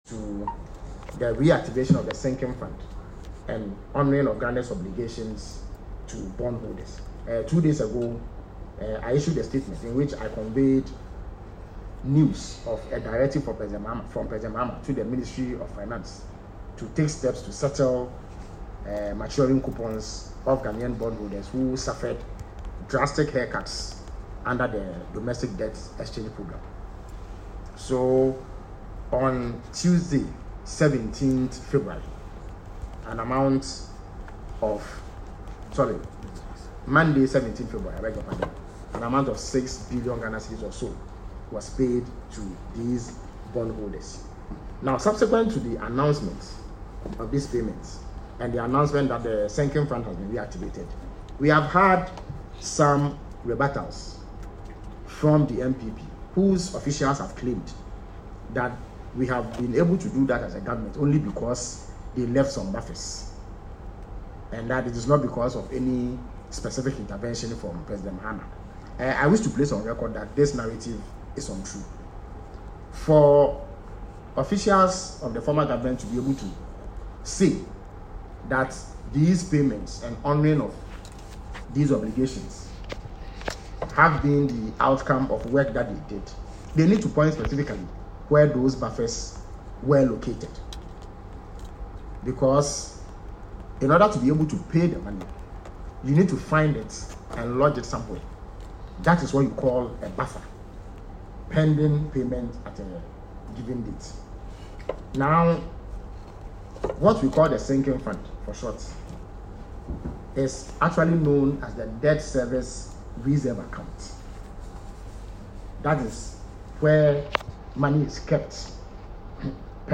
At a press conference on Wednesday, Kwakye Ofosu challenged the Karaga MP to identify where these supposed buffers were lodged within the sinking fund.